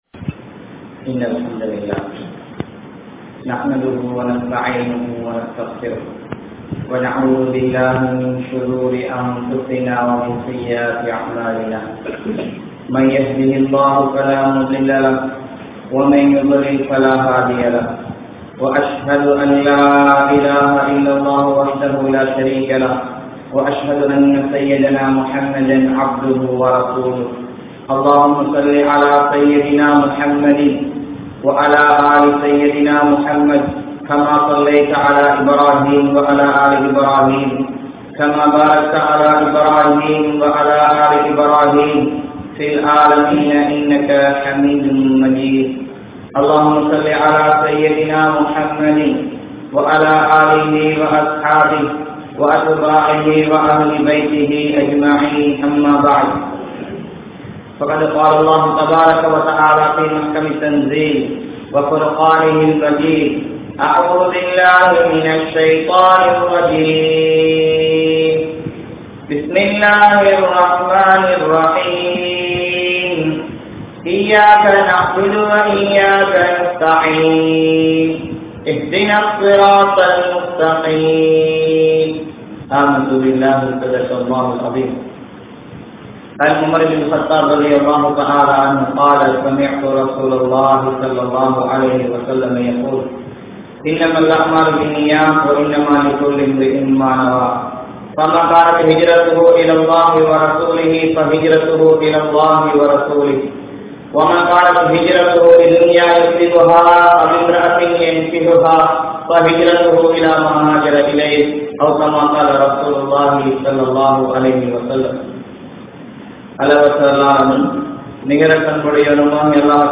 Anumathi Perum Muraihal (அனுமதி பெறும் முறைகள்) | Audio Bayans | All Ceylon Muslim Youth Community | Addalaichenai
Masjidhul Hamidiya Jumua Masjidh